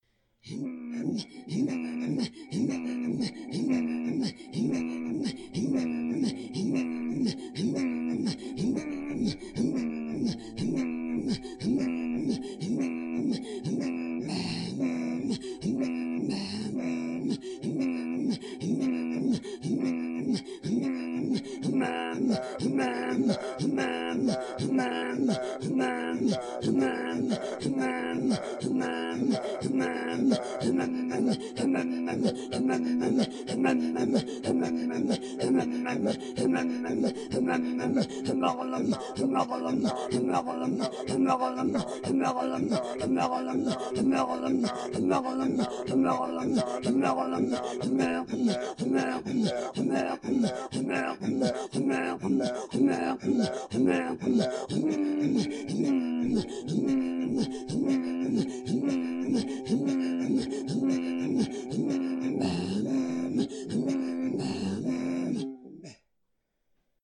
throatsinging